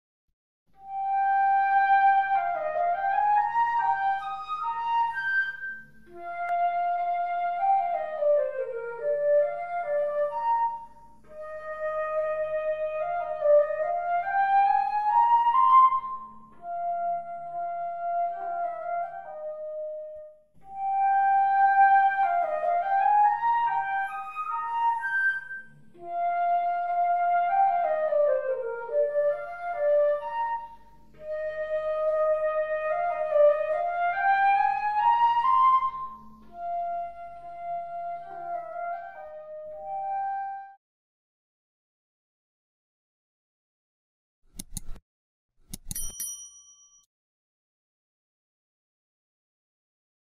VIENTO MADERA
Sonido+De+Flauta+Travesera (audio/mpeg)
FLAUTA TRAVESERA